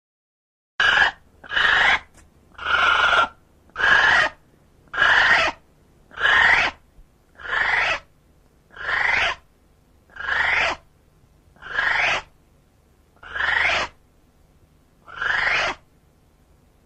Звуки птицы киви
На этой странице собраны звуки птицы киви — уникальной нелетающей птицы из Новой Зеландии. Вы можете слушать онлайн или скачать её голоса в формате mp3: от нежных щебетаний до характерных криков.